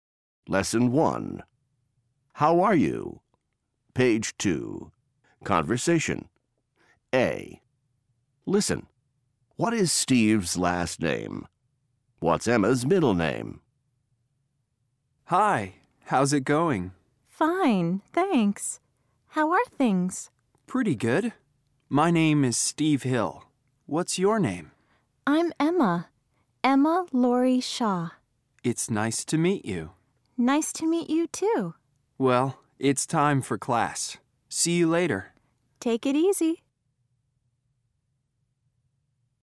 Part 2 - Conversation
فایل صوتی مکالمه بدون متن